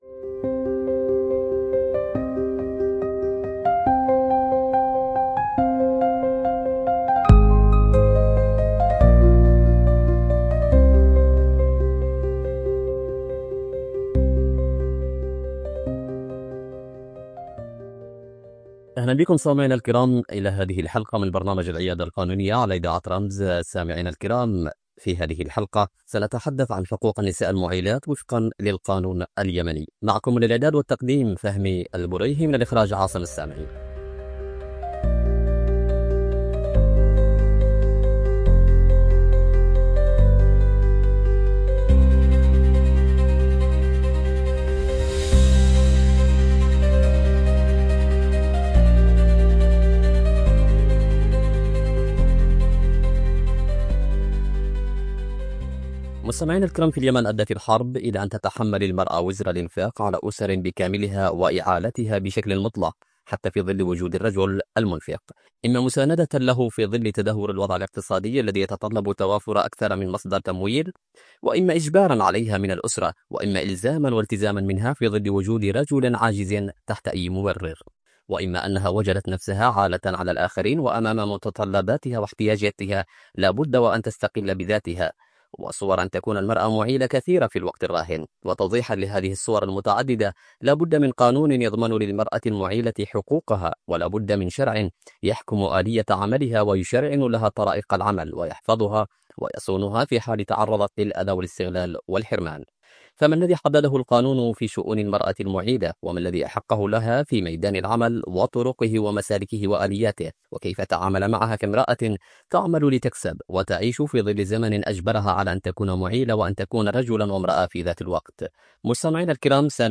حوار قانوني
📻 عبر إذاعة رمز